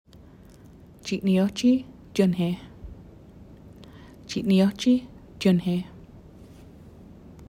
pronunciation) by Nancy Deleary was installed on the interior fireplace at East Lions Community Centre (1731 Churchill Ave, London On), and unveiled on June 27, 2024.